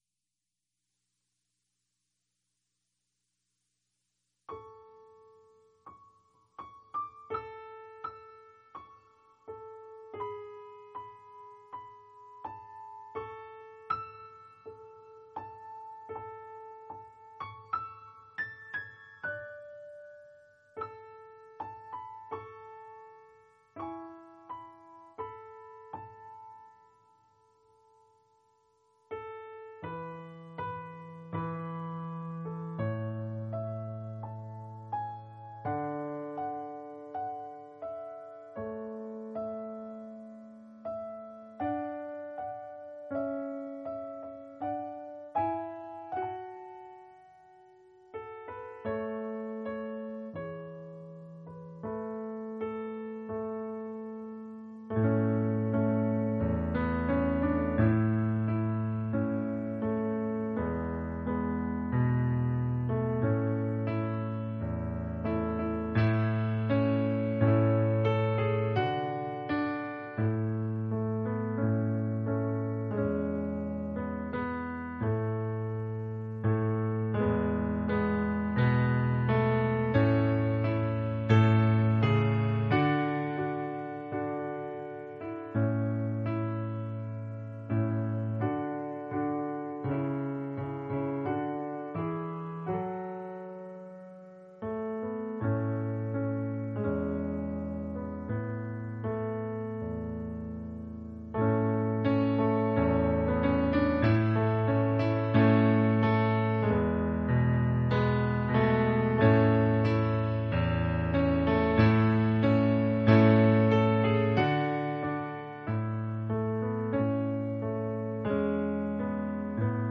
An expository teaching on this often misunderstood chapter, with emphasis on considering its context and remembering that we must never bend the Scriptures to match our feelings.